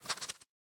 sounds / mob / fox / idle5.ogg